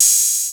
hihatopen.wav